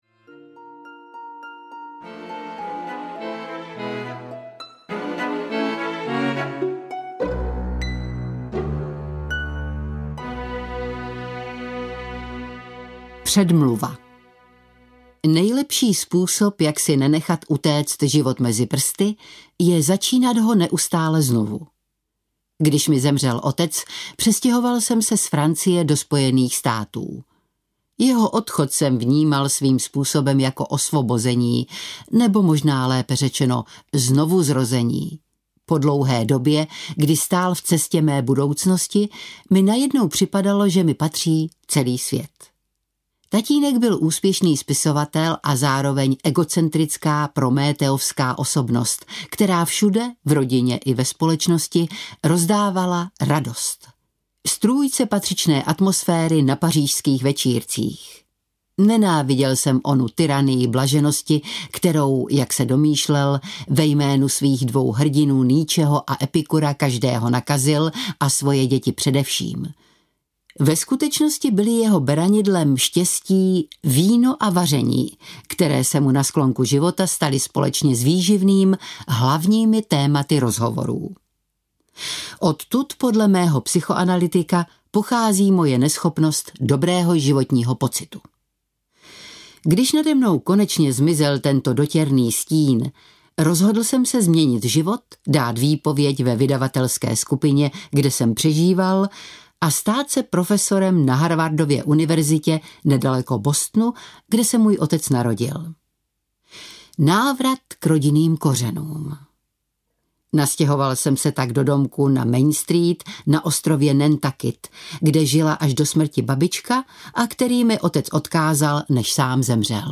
Trhačka zubů audiokniha
Ukázka z knihy
• InterpretSylva Talpová